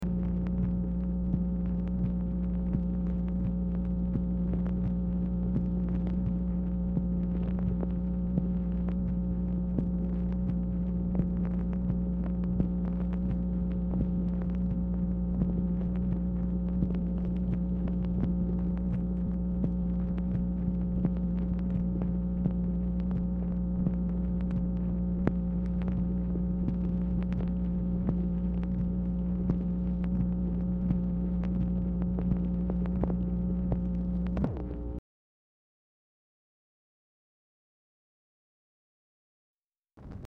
Telephone conversation # 10760, sound recording, MACHINE NOISE, 9/13/1966, time unknown | Discover LBJ
Format Dictation belt